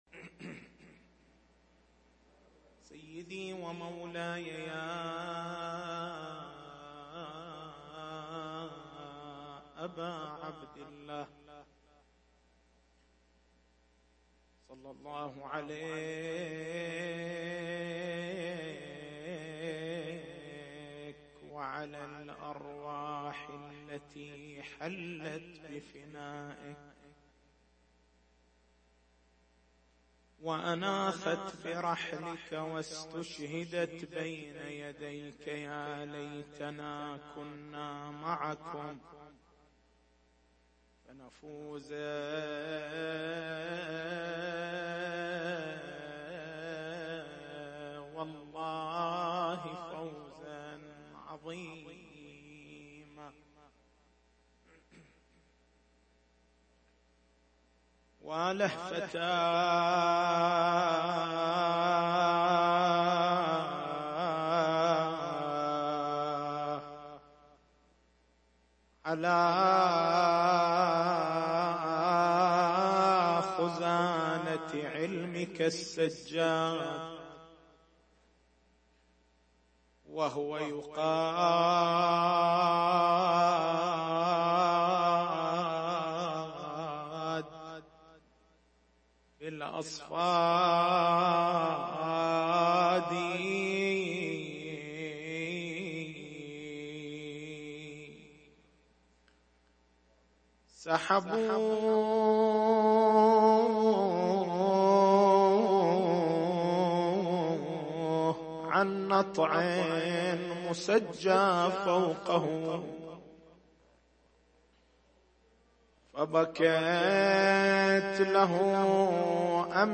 تاريخ المحاضرة
عشرات حسينيّة